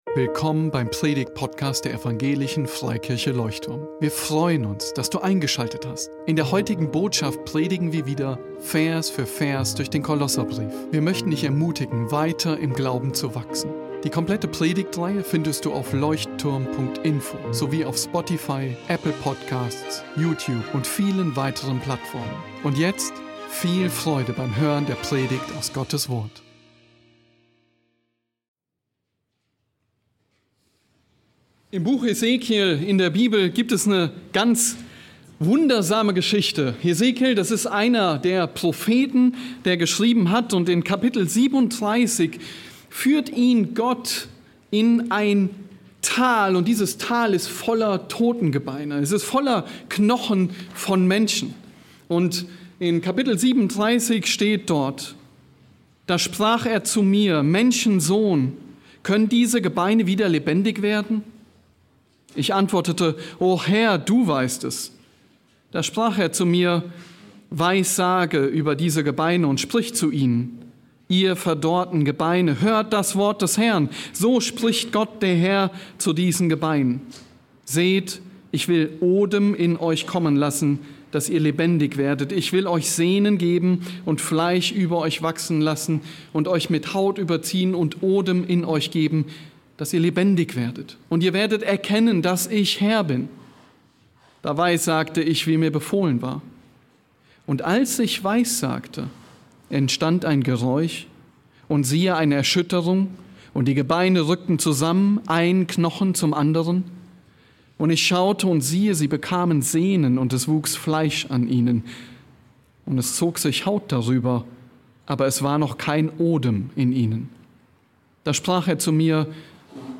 Besuche unseren Gottesdienst in Berlin.